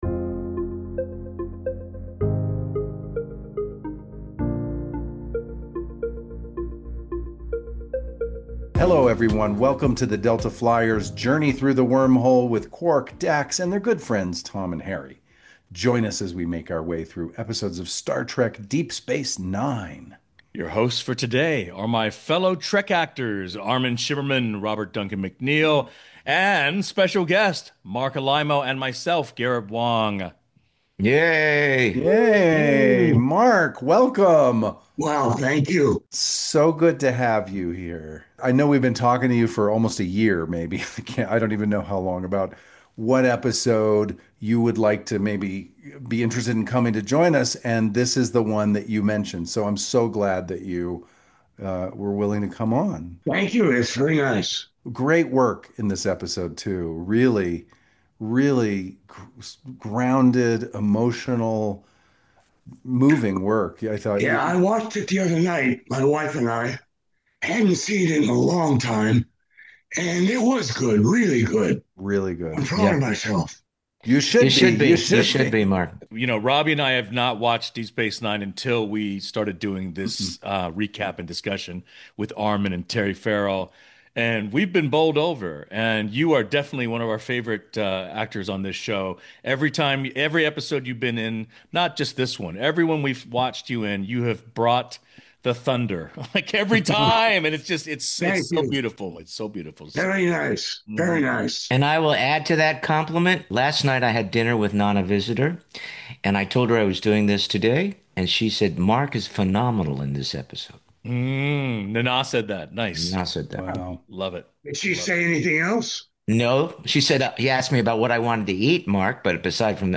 In each podcast release, they will recap and discuss an episode of Star Trek: Deep Space Nine. This week’s episode, Indiscretion, is hosted by Garrett Wang, Robert Duncan McNeill, Armin Shimerman, and special guest Marc Alaimo.